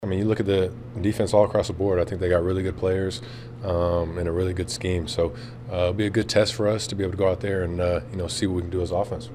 Packers quarterback Jordan Love talks about facing a strong Denver defense.